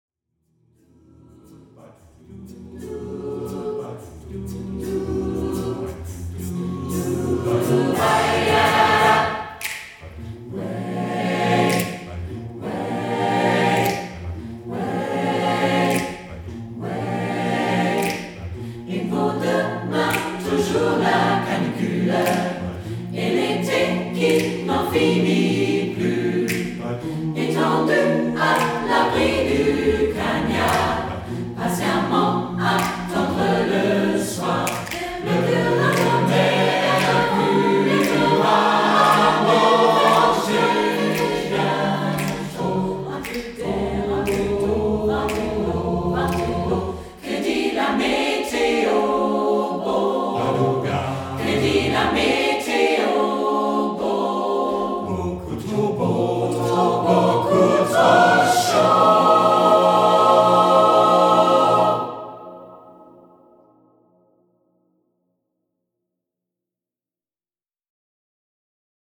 • Dem Tonstudio Tessmar in Hannover